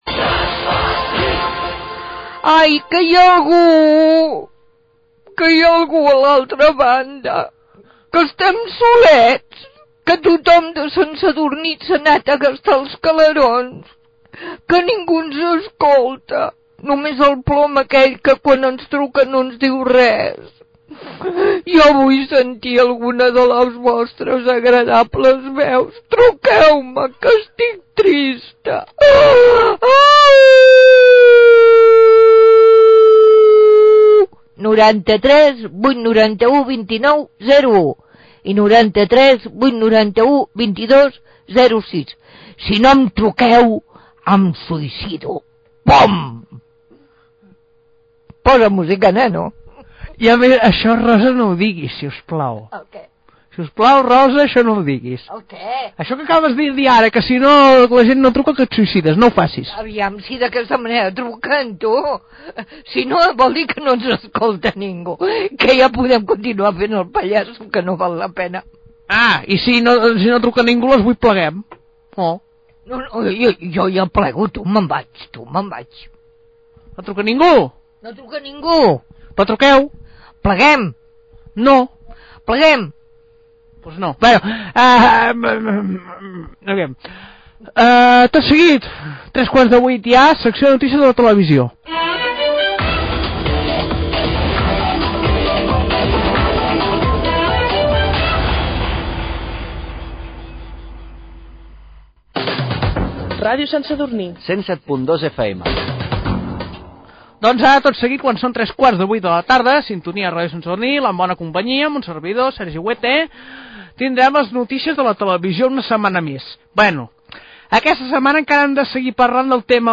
Demanda de trucades telefòniques, indicatiu, notícies de la televisió
Entreteniment
FM